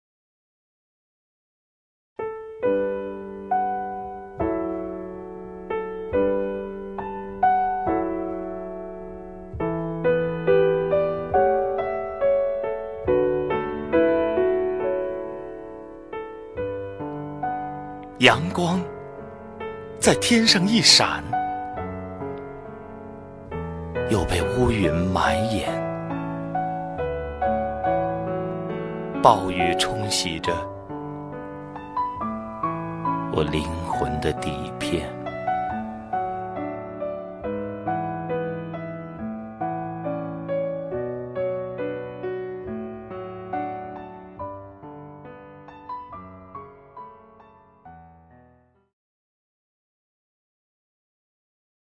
赵屹鸥朗诵：《摄》(顾城) 顾城 名家朗诵欣赏赵屹鸥 语文PLUS